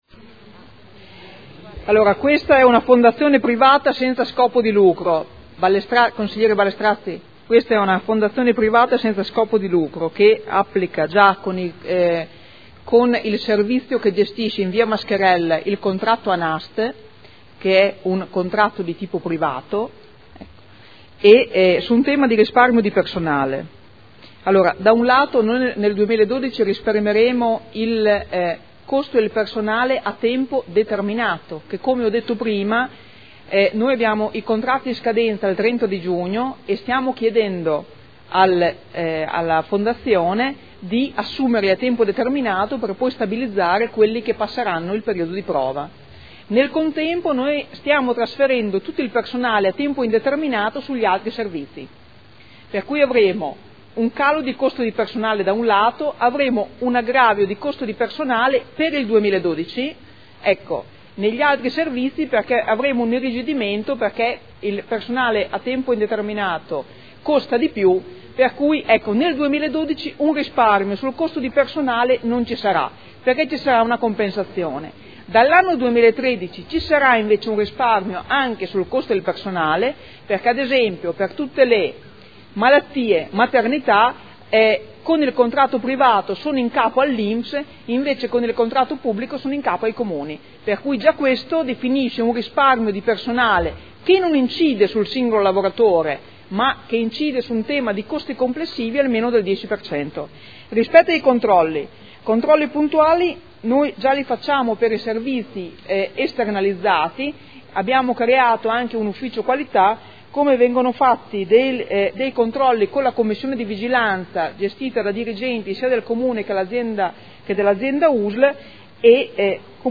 Seduta del 18/06/2012. Conclude dibattito su proposta di deliberazione.